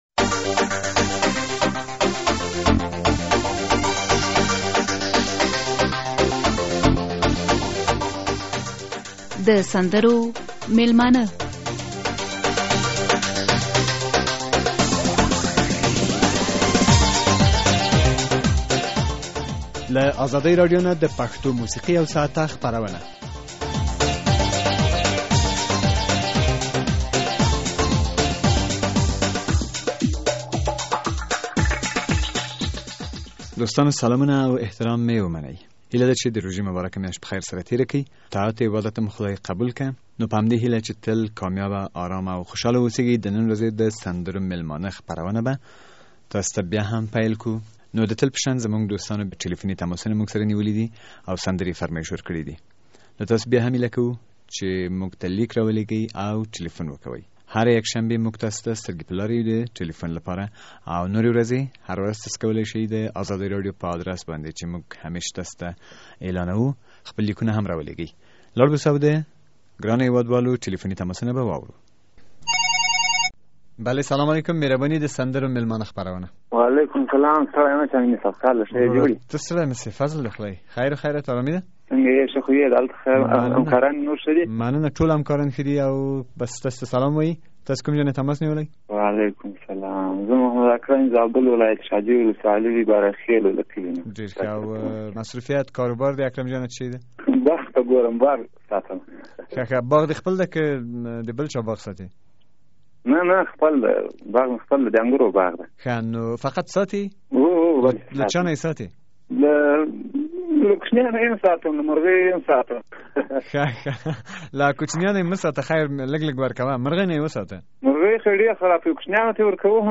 د پنجشنبې د ورځې سندریز پروګرام اوري